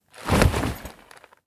leather_cloth_02.wav.mp3